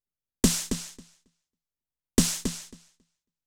SD DELAYED-L.wav